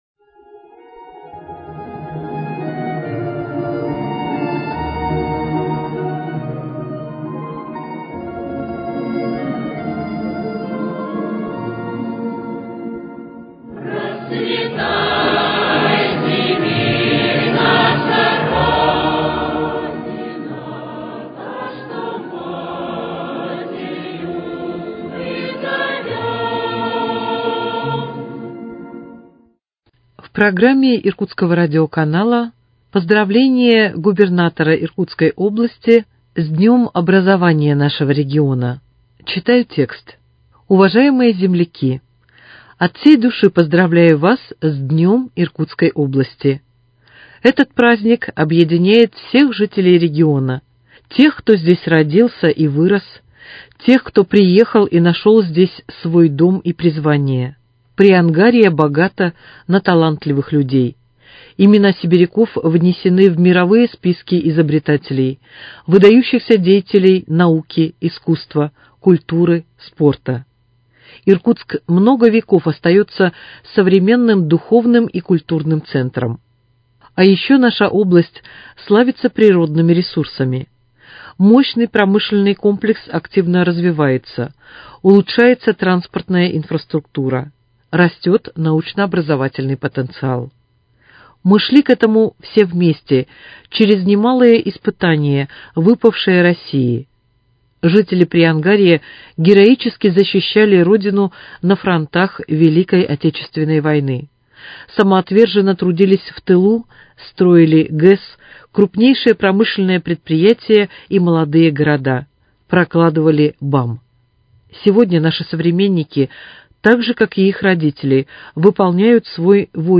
Поздравление Губернатора Игоря Кобзева с Днём Иркутской области.